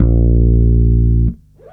12-A#1.wav